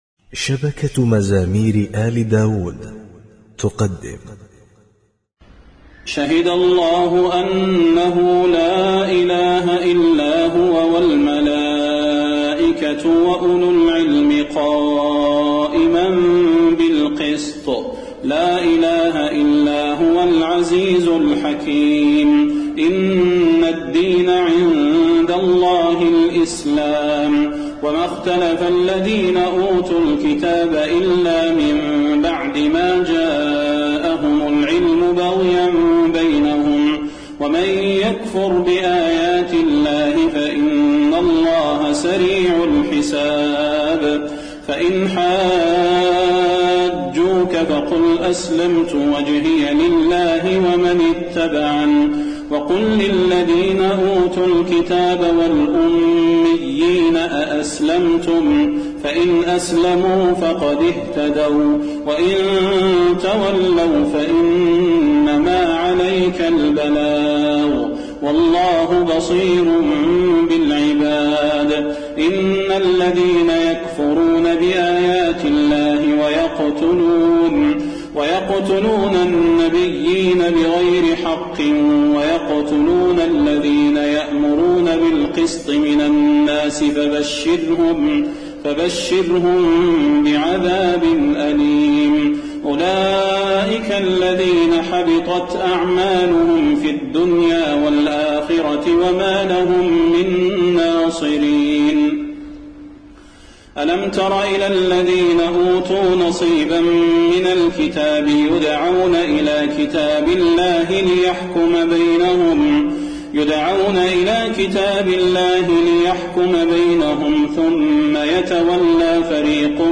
تهجد ليلة 23 رمضان 1432هـ من سورة آل عمران (18-132) Tahajjud 23 st night Ramadan 1432H from Surah Aal-i-Imraan > تراويح الحرم النبوي عام 1432 🕌 > التراويح - تلاوات الحرمين